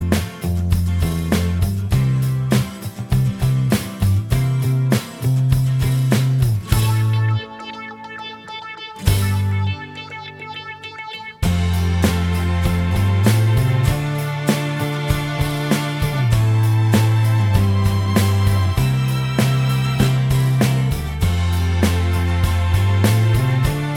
Minus Electric Guitar Pop (1980s) 4:15 Buy £1.50